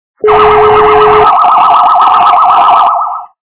» Звуки » звуки для СМС » Инопланетяне - Летающая тарелка
При прослушивании Инопланетяне - Летающая тарелка качество понижено и присутствуют гудки.
Звук Инопланетяне - Летающая тарелка